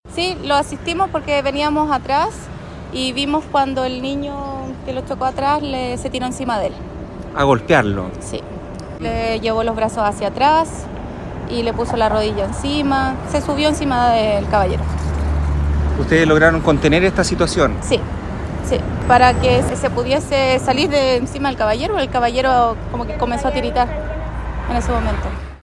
Así lo contó una de ellas a La Radio después de haber esperado que el hombre fuese atendido por el SAMU.